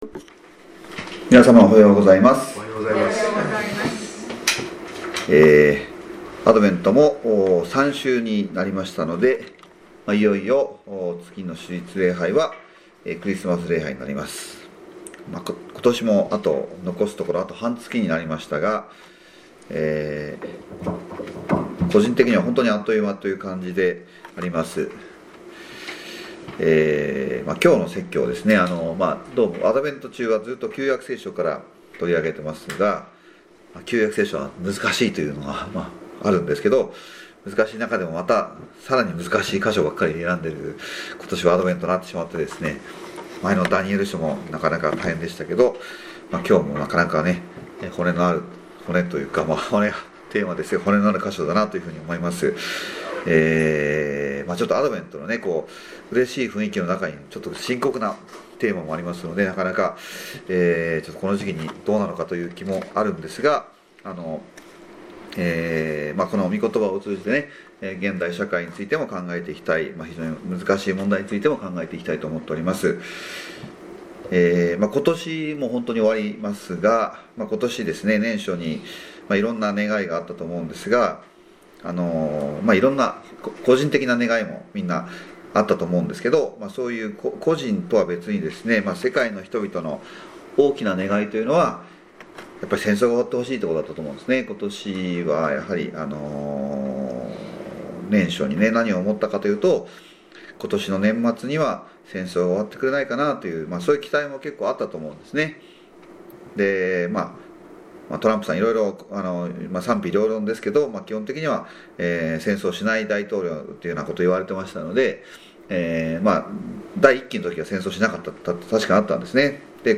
イスラエルの復興エゼキエル書37章1～28節 - 中原キリスト教会